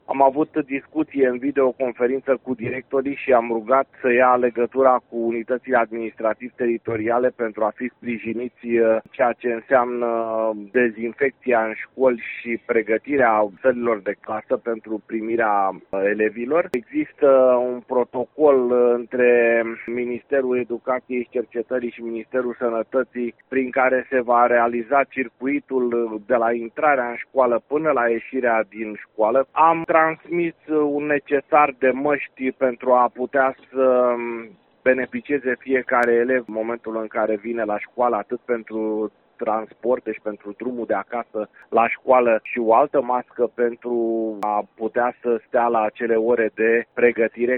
Inspectorul școlar general al județului Harghita, Cristinel Glodeanu: